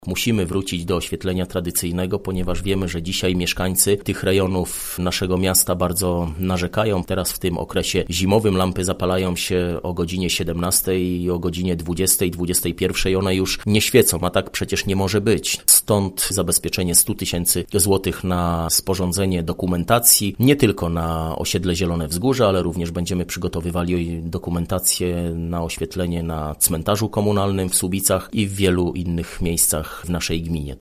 – W tegorocznym budżecie gminy zapisaliśmy pieniądze na wykonanie projektu linii elektroenergetycznej, która umożliwi budowę oświetlenia na osiedlu Zielone Wzgórza – mówi Mariusz Olejniczak, burmistrz Słubic: